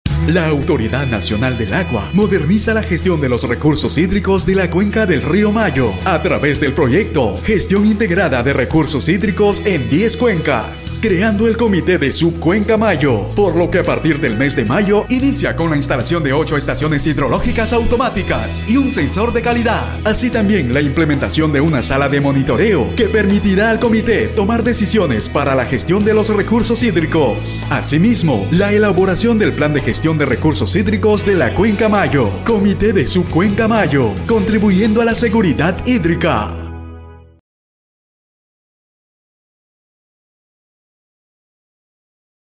Spot: Actividades contempladas en la implementación del Proyecto Gestión Integrada de Recursos Hídricos | Mayo